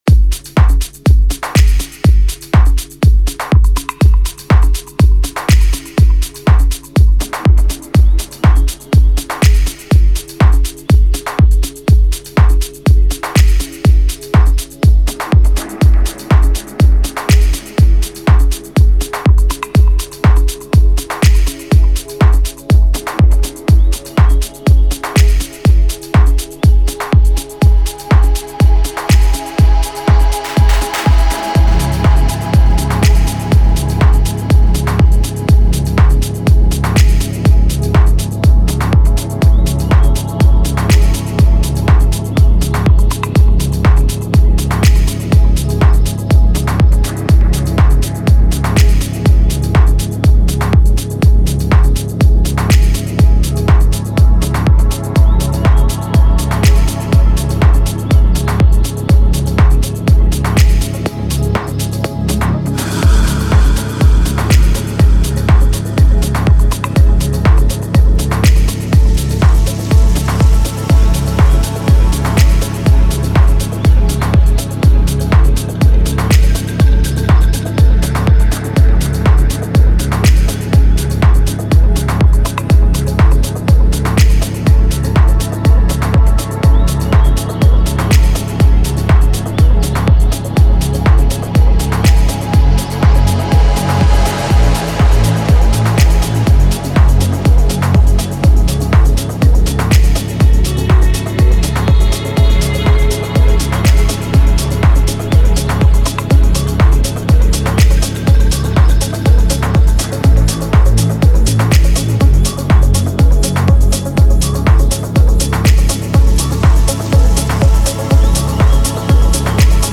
موسیقی کنار تو